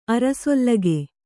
♪ arasollage